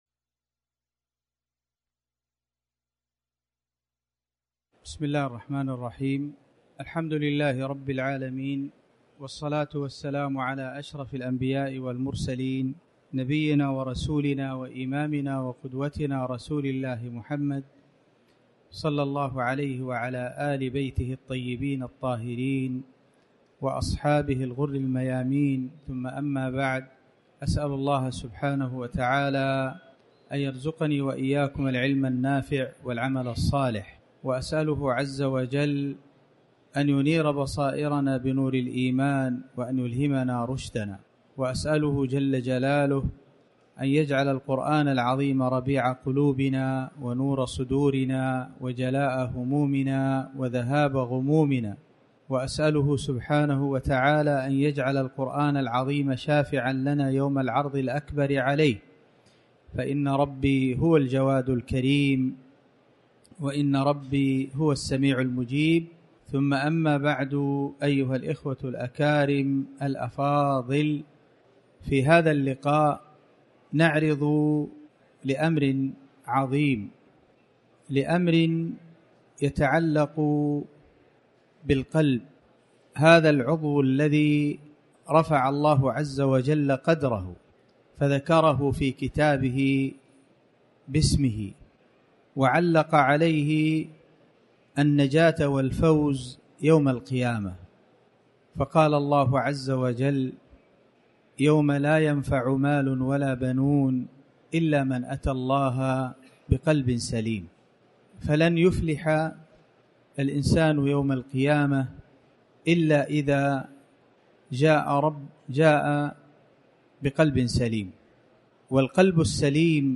26ذو-الحجة-محاضرة-رفع-الباس-عن-حديث-النعس-والهم-والوسواس-1.mp3